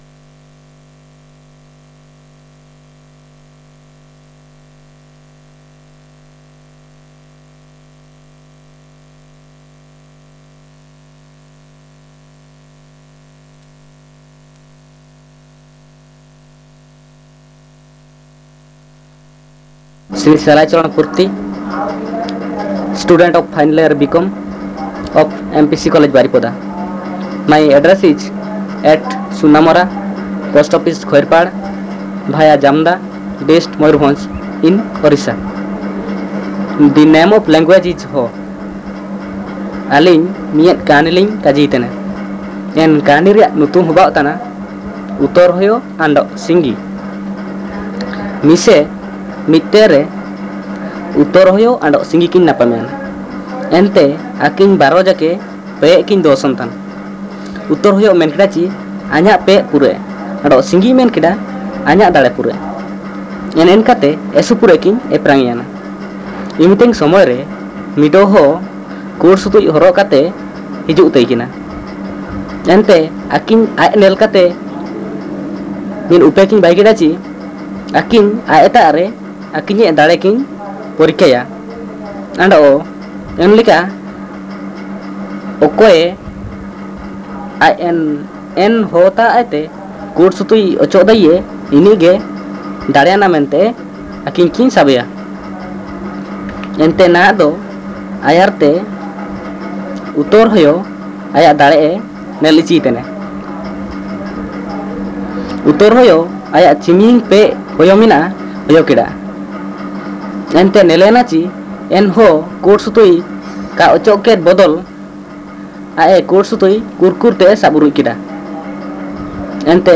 Conversation in Ho & Bhumij